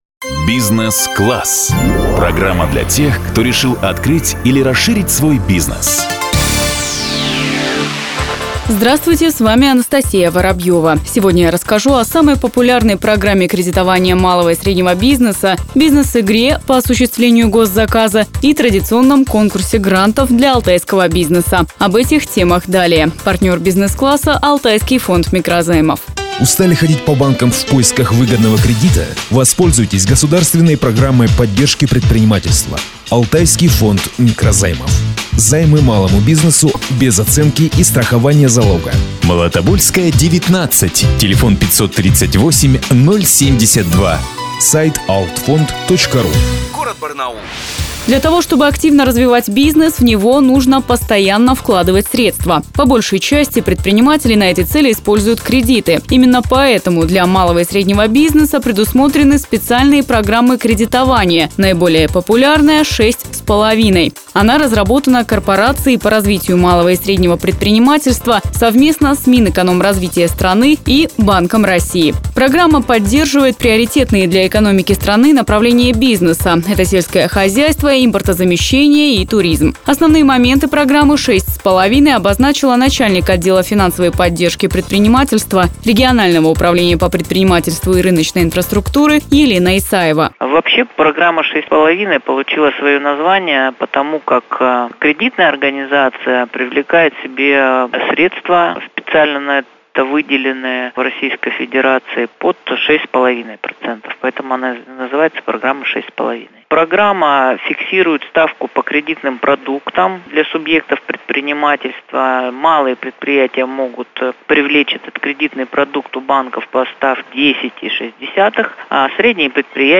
Она вышла в эфир 10 июля 2017 года.